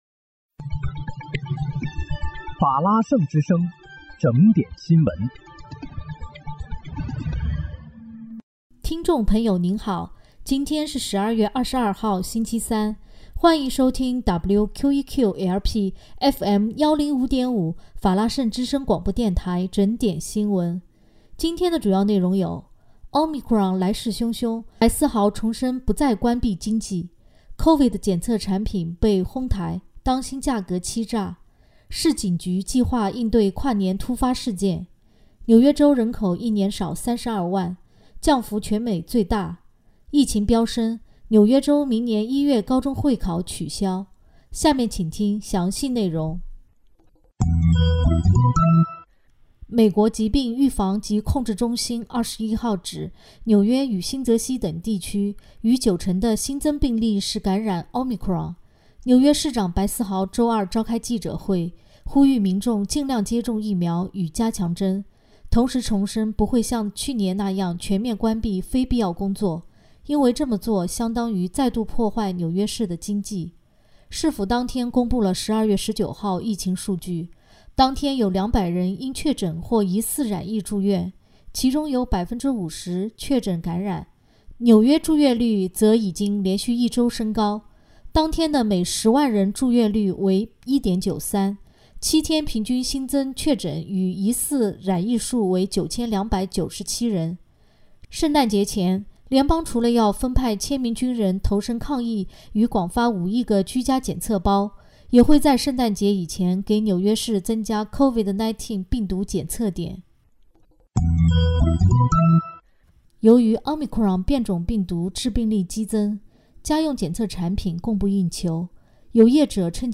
12月22日（星期三）纽约整点新闻
听众朋友您好！今天是12月22号，星期三，欢迎收听WQEQ-LP FM105.5法拉盛之声广播电台整点新闻。